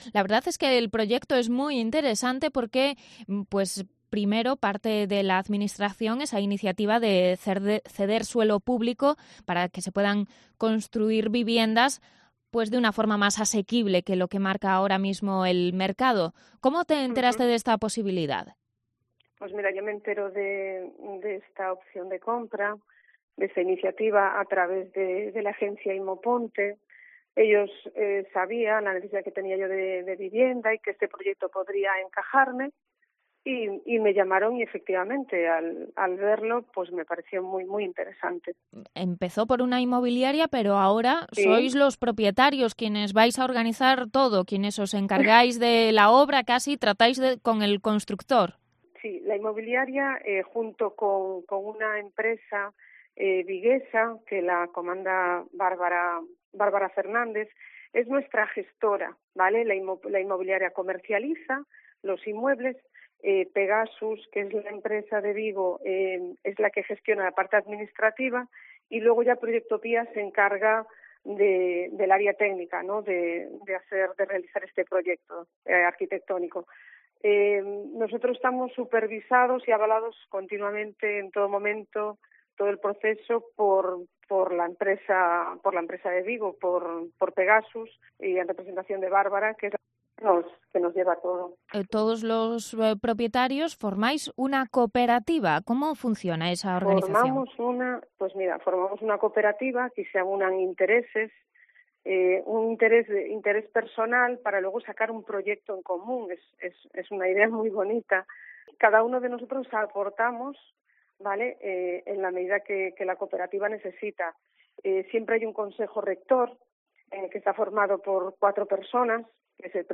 Entrevista a una de las futuras residentes de las viviendas de Valdecorvos